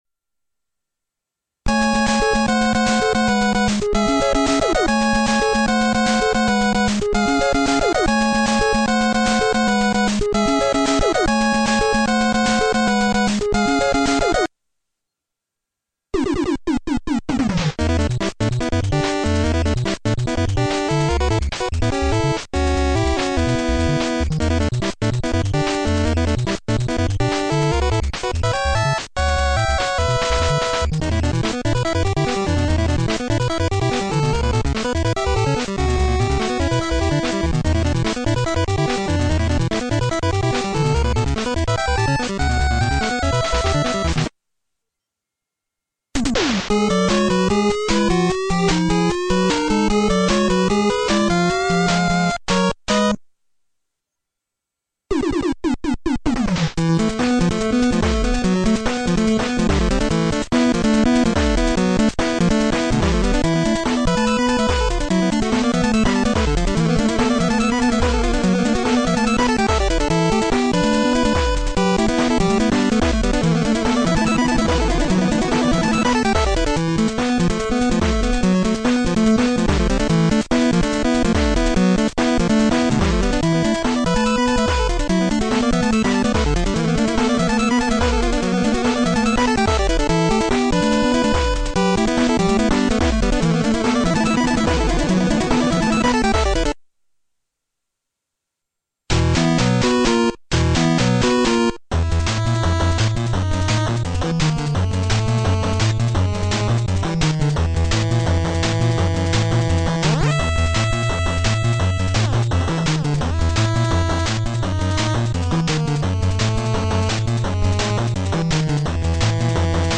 BPM144-255
Audio QualityCut From Video